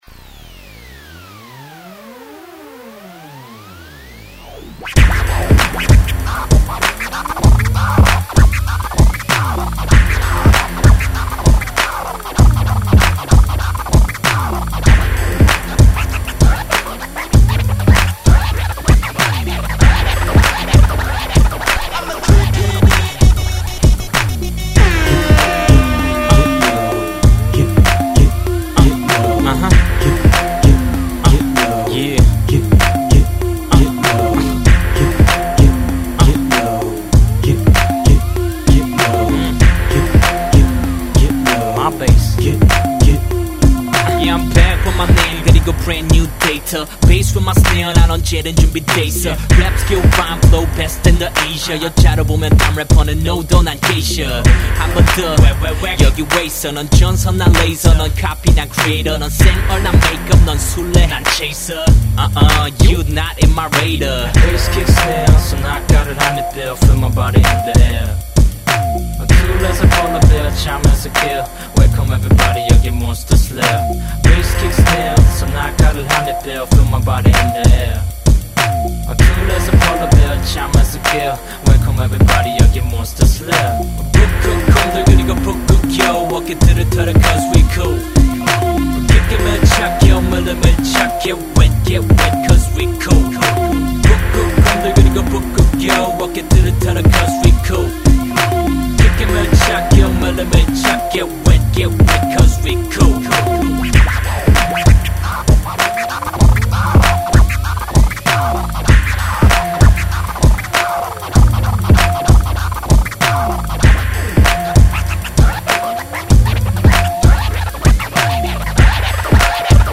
• [국내 / REMIX.]
비트메이킹 하고 아카펠라 입혀서 리믹스 하고 스크래치도 해봤어요 ㅎ